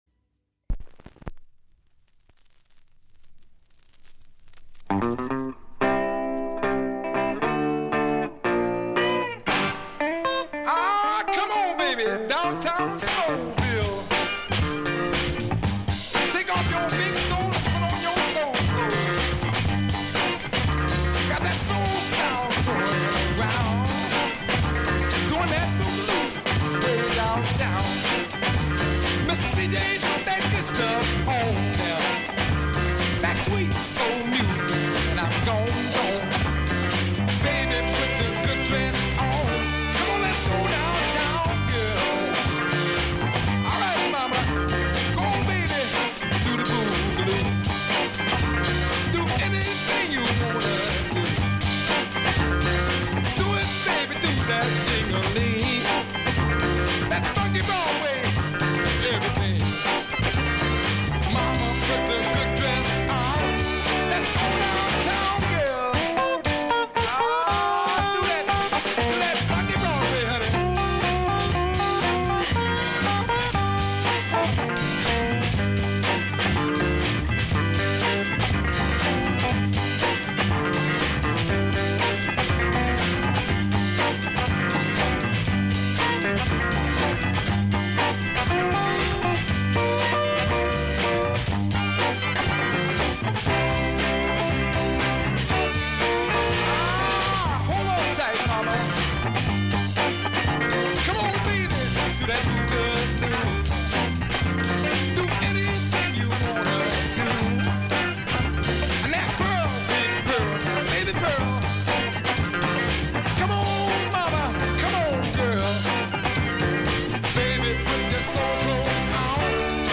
Music behind DJ